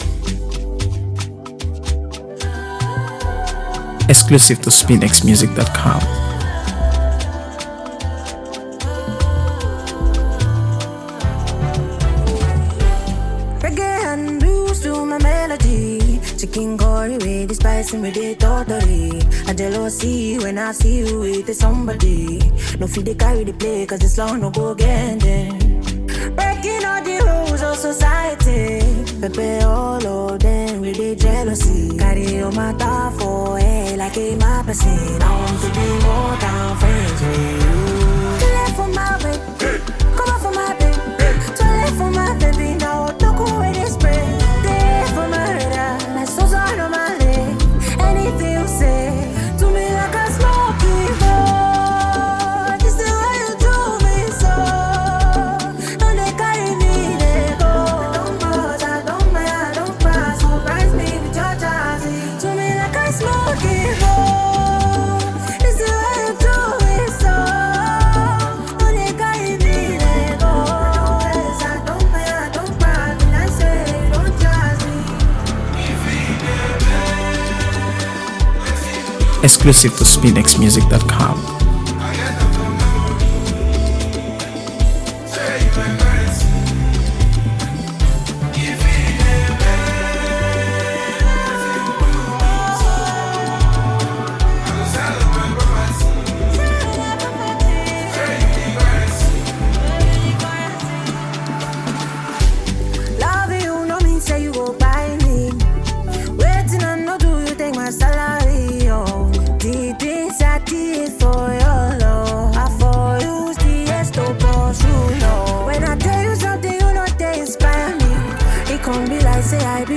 AfroBeats | AfroBeats songs
catchy single
Over catchy sounds
seductive voice shine
passionate love song